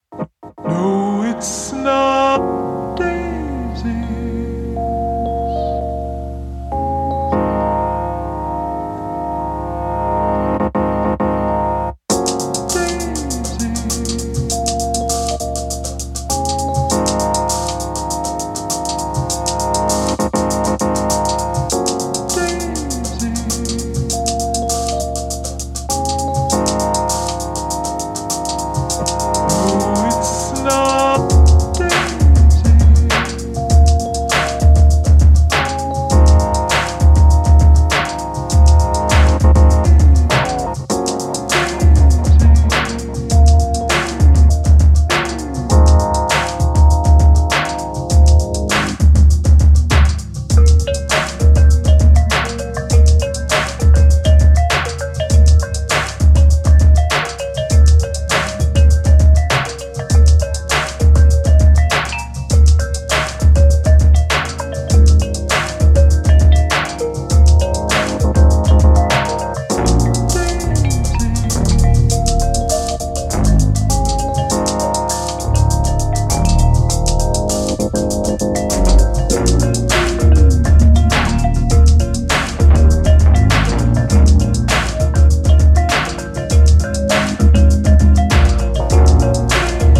straight up house jams, hints of techno, nods to broken beat